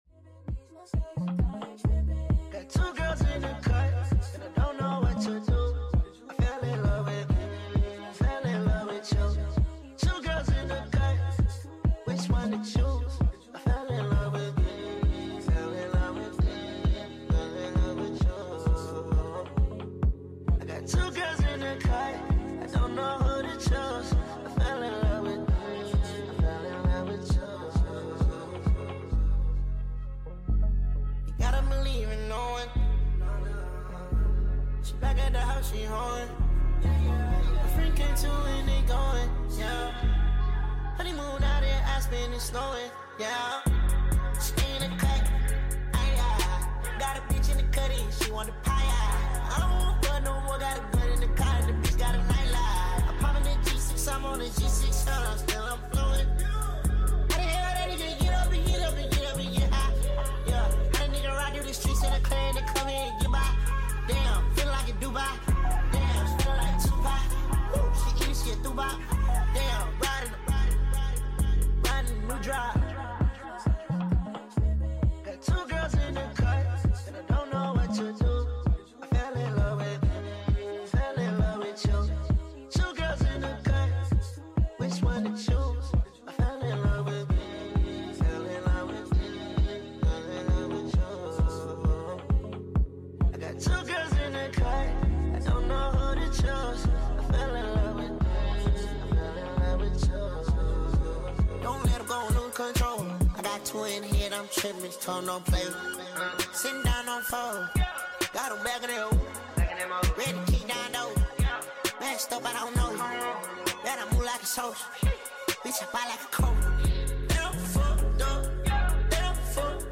Full Sped Up version